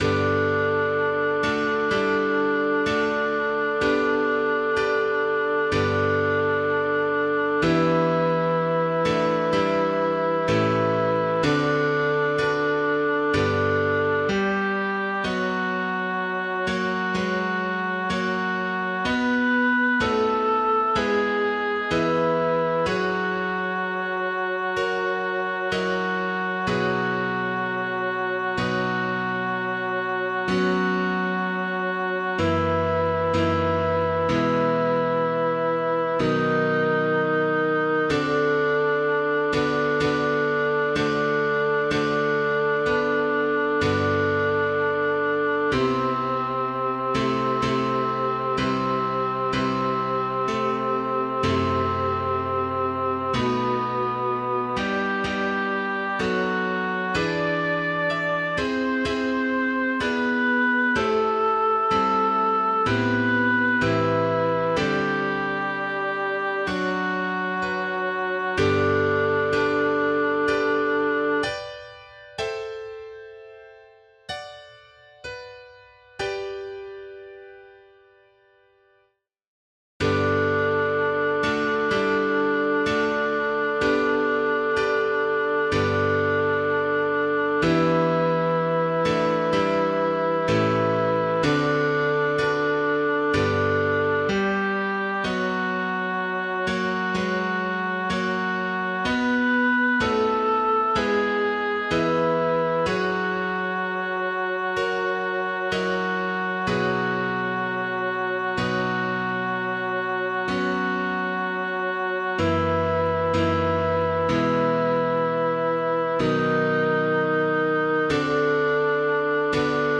Bas 1
ave maris stella-b1.mp3